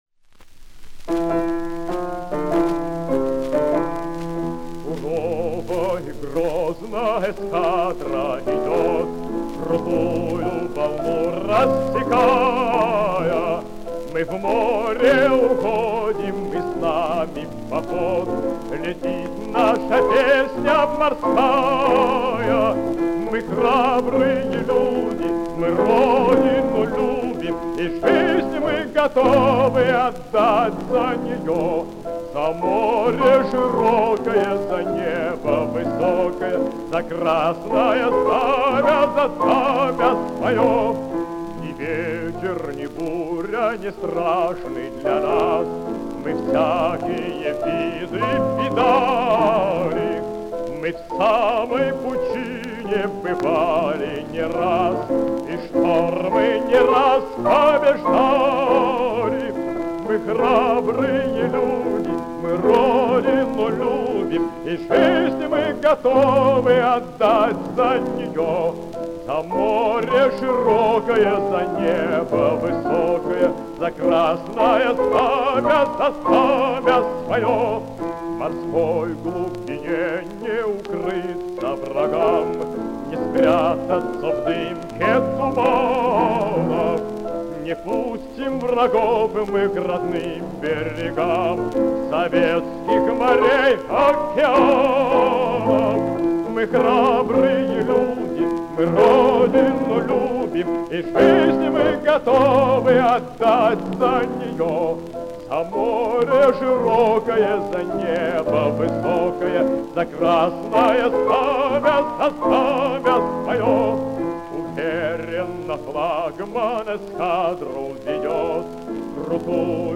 Ф-но